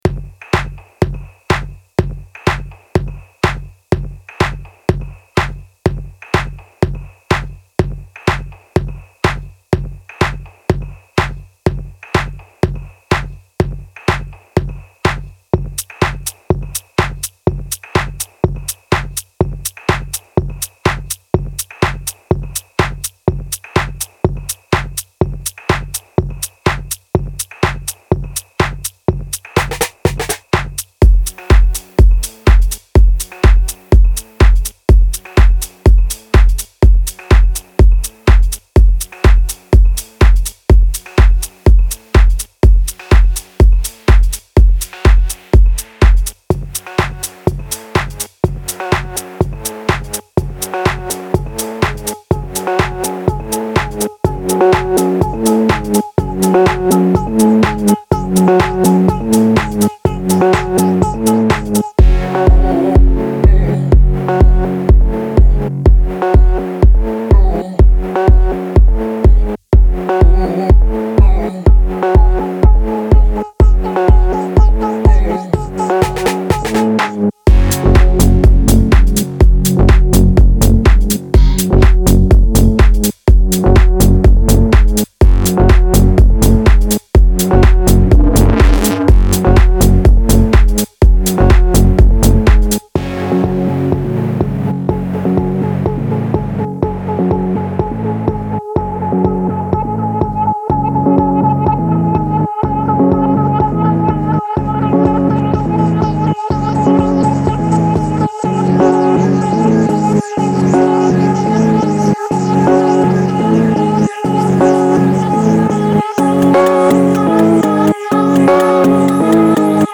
это энергичная трек в жанре хаус